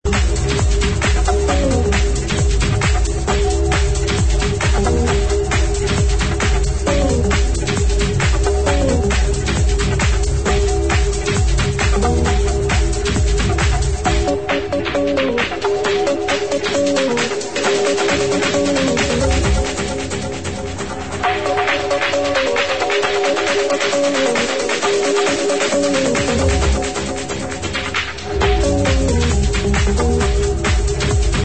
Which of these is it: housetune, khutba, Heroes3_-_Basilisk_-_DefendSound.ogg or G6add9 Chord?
housetune